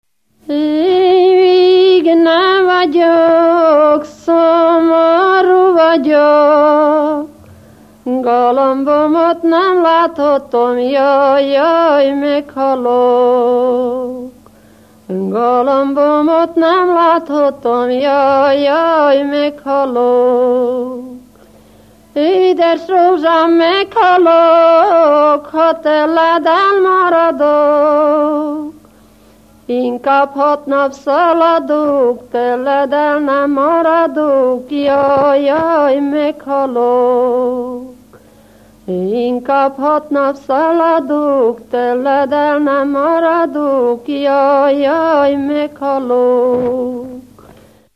Moldva és Bukovina - Moldva - Lészped
Stílus: 7. Régies kisambitusú dallamok
Szótagszám: 5.5.8.5
Kadencia: 5 (b3) 1 1